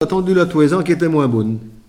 Mots Clé tondeur(s), tonte ; Localisation Saint-Hilaire-des-Loges
Catégorie Locution